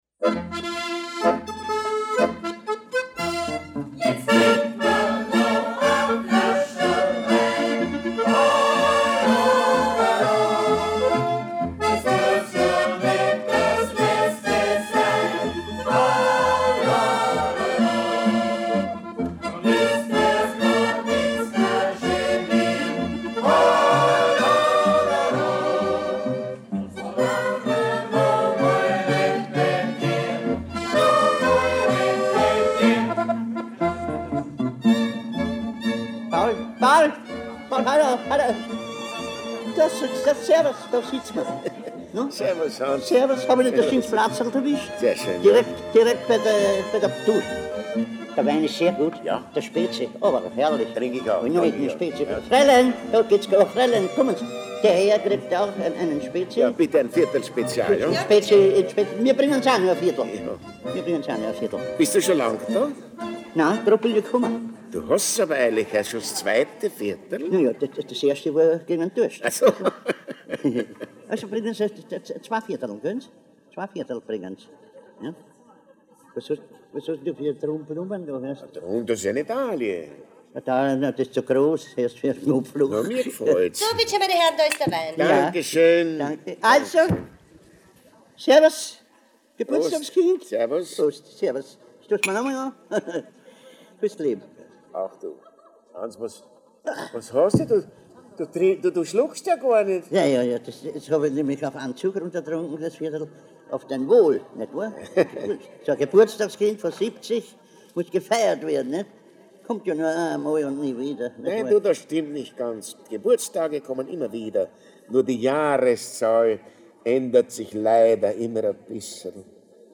The album has a playing time of about 40 minutes.
Viennese songs